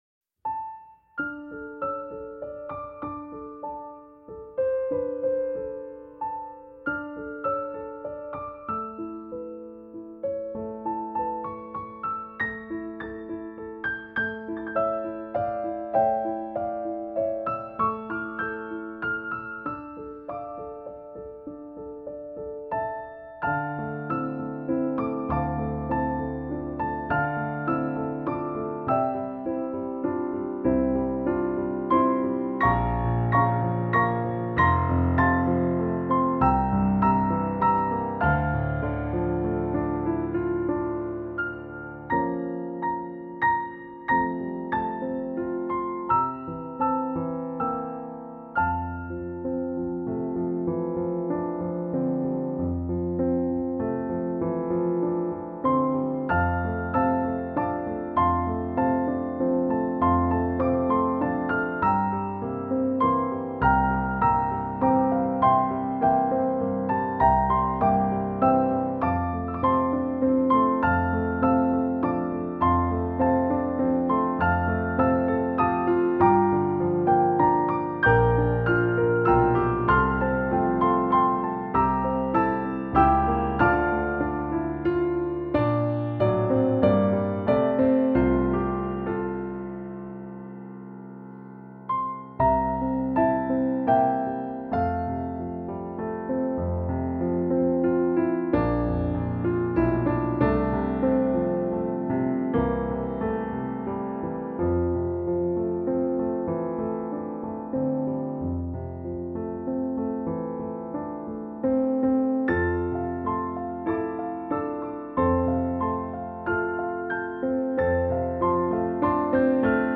instrumentation: Piano Duet
level: Early Intermediate
peaceful assurance